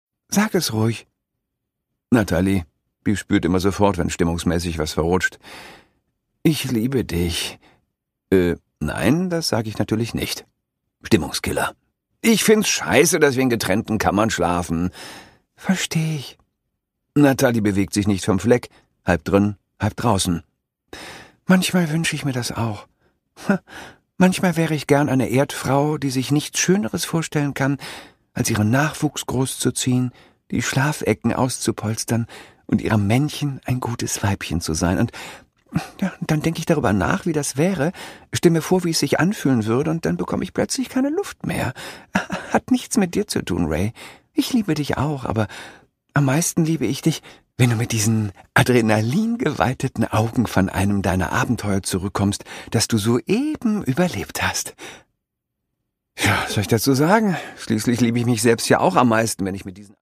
Produkttyp: Hörbuch-Download
Gelesen von: Christoph Maria Herbst